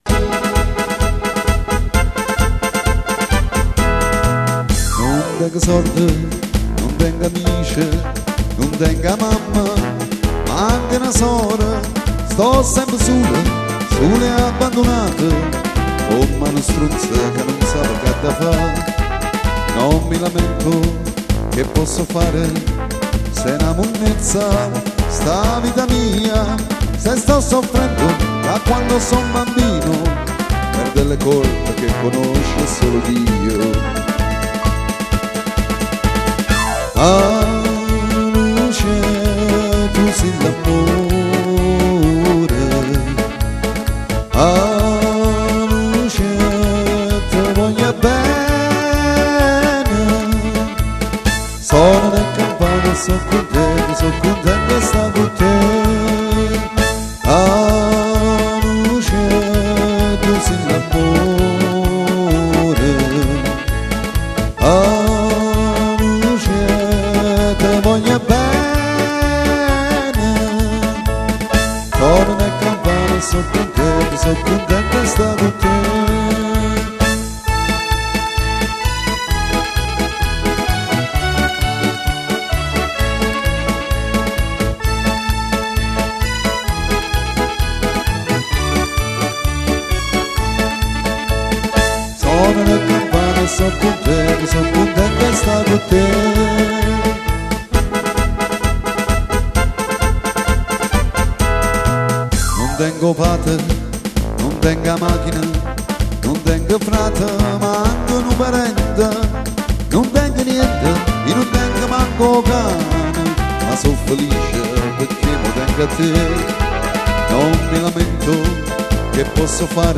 PASODOPLE